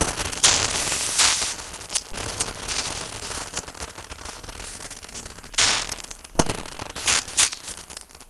elec_lightning_magic_arc_loop1.wav